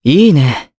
Pavel-Vox_Happy4_jp.wav